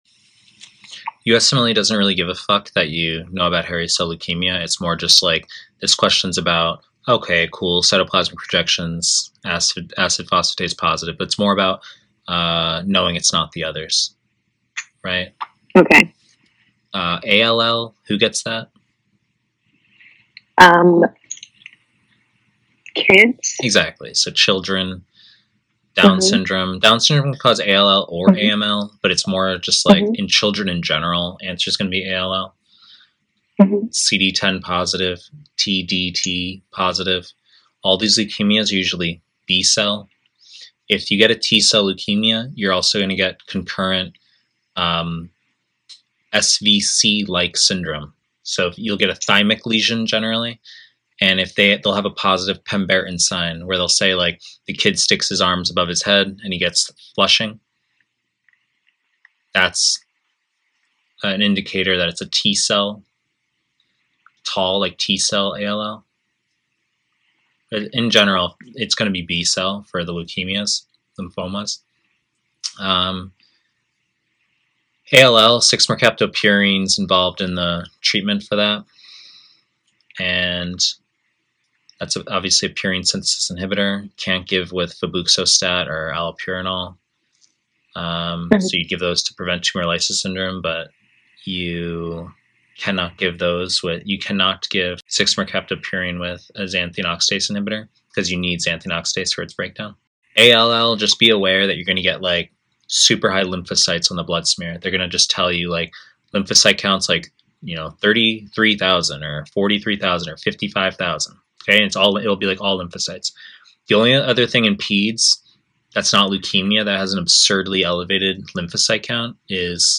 Pediatrics / Pre-recorded lectures